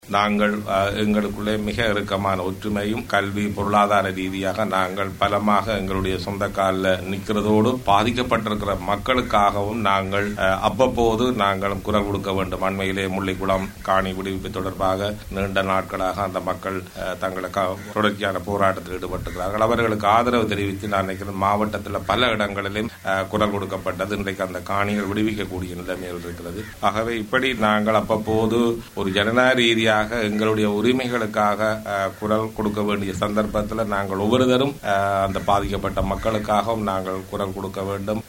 மன்னாரில் இடம்பெற்ற நிகழ்வொன்றில் உரையாற்றும் போது அவர் இதனைக் கூறியுள்ளார்.